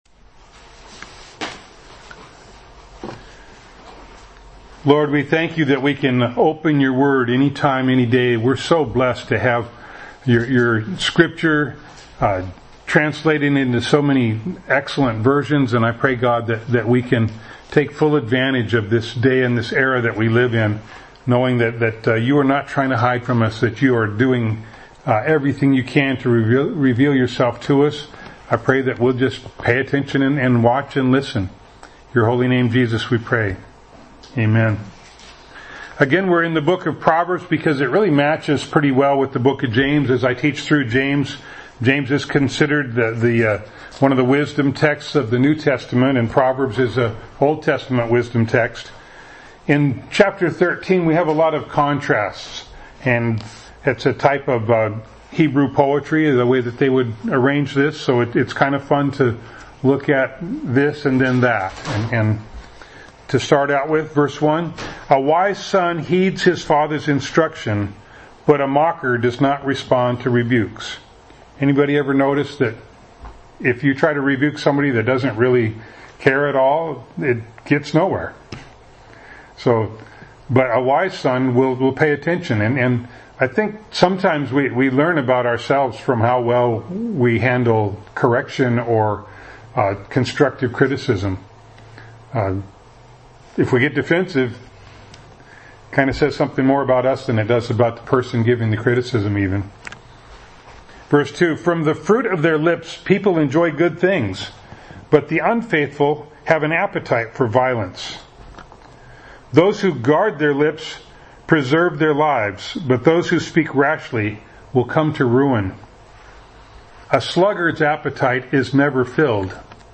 James 1:18 Service Type: Sunday Morning Bible Text